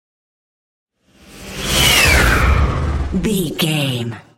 Airy whoosh large
Sound Effects
futuristic
whoosh
sci fi